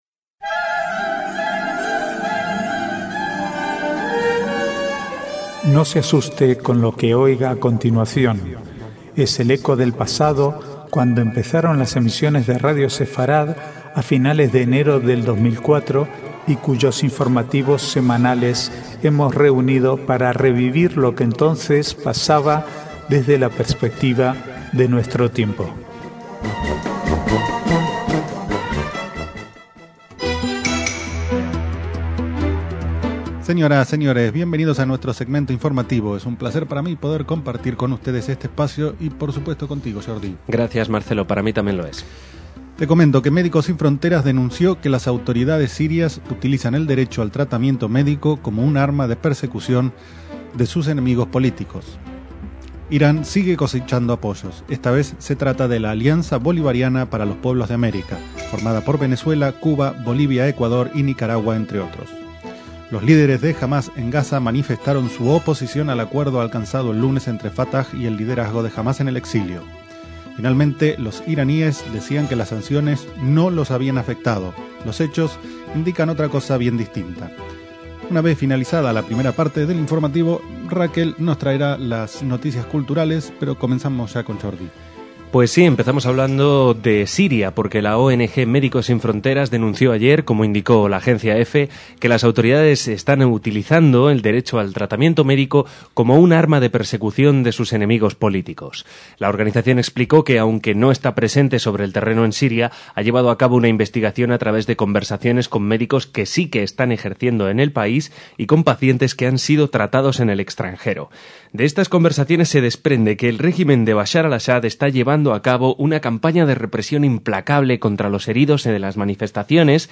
informativos semanales